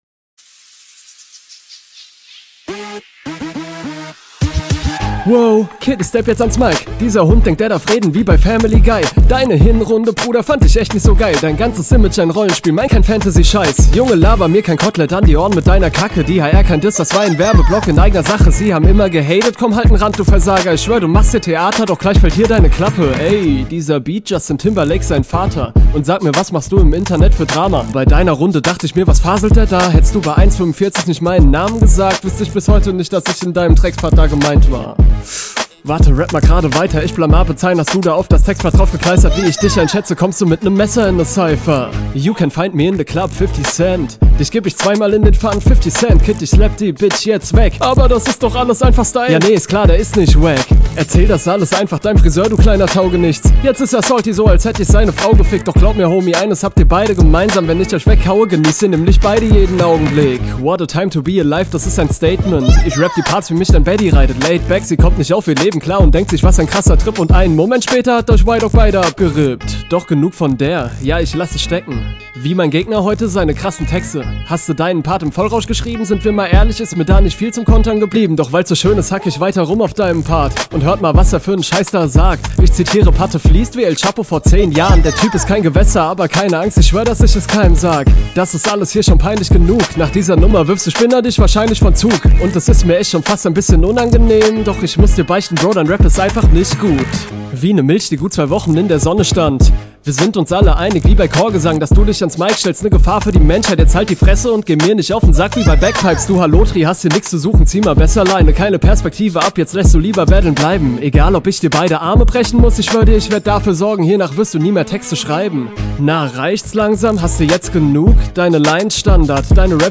Raptechnisch und von den Betonungen finde ich das cool, hier kommt dieses Laidback-mäßige echt gut.